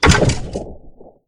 snd_skeletonbossdie.ogg